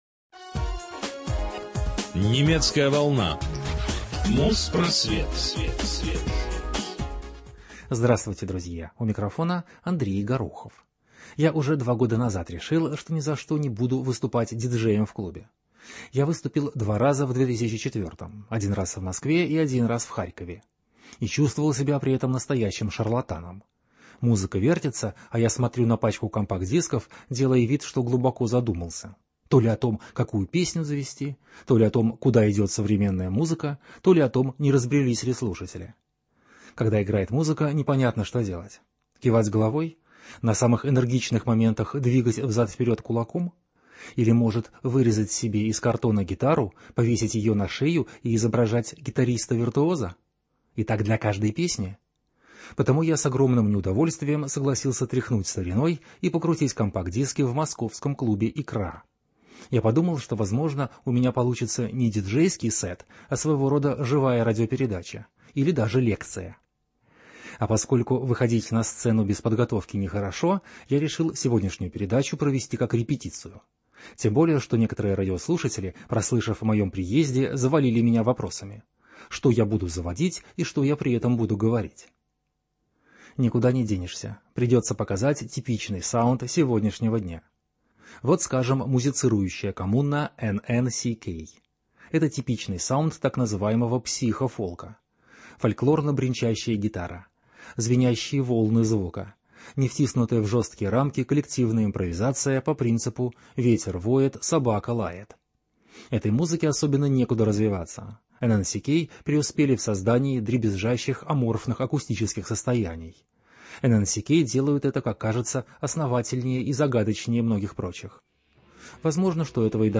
Репетиция выступления в Москве: лекция о текущем положении и эскиз DJ-сета.